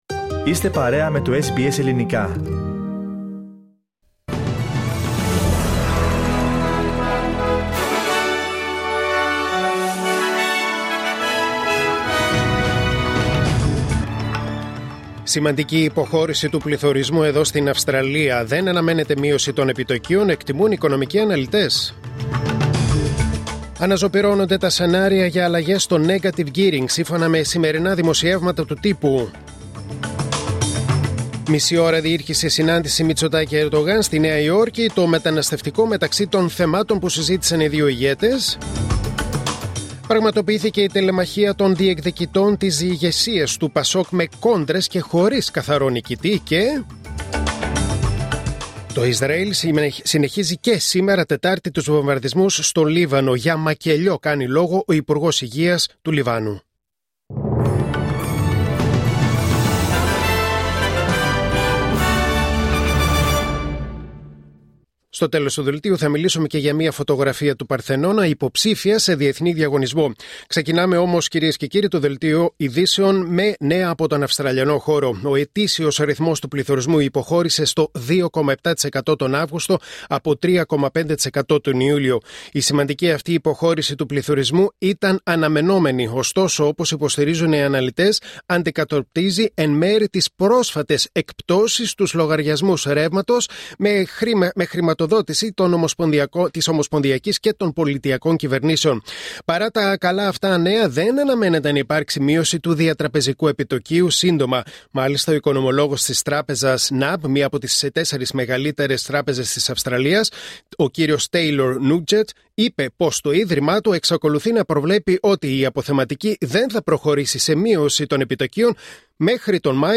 Δελτίο Ειδήσεων Τετάρτη 25 Σεπτεμβρίου 2024